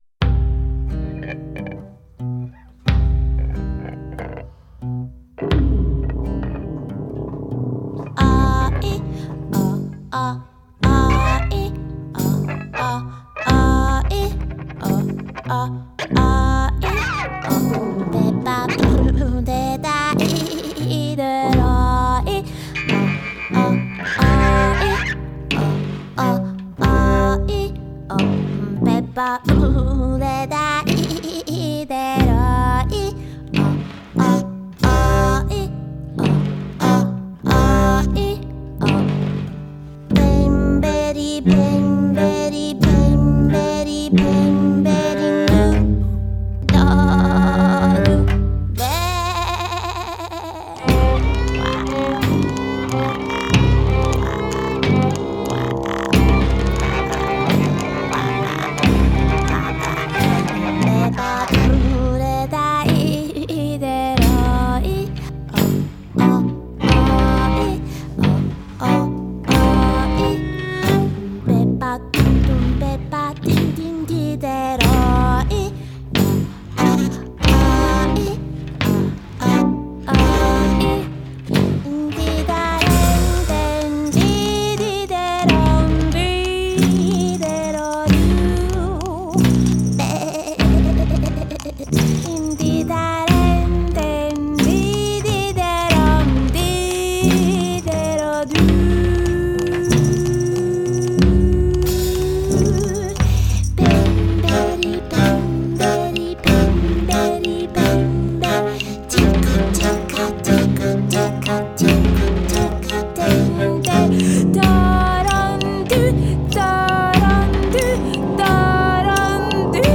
Des compositions étranges tantôt inquiétantes
mais toujours sublimées par un violoncelle omniprésent.